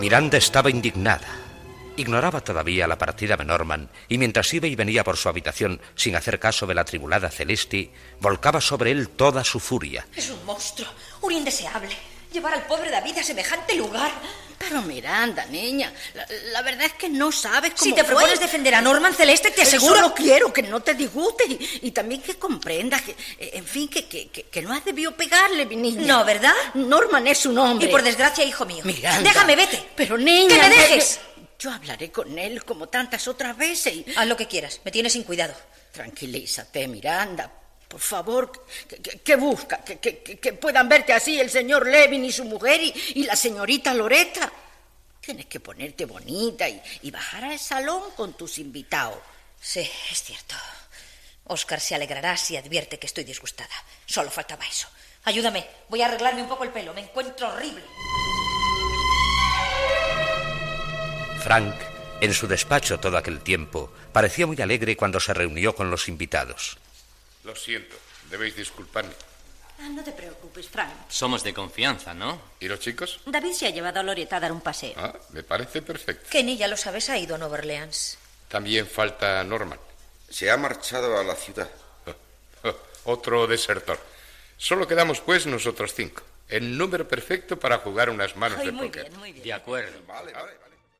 Fragment del capítol 27. Diàleg entre Celeste i Miranda, els invitats parlen al despatx
Ficció